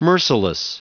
Prononciation du mot merciless en anglais (fichier audio)
Prononciation du mot : merciless
merciless.wav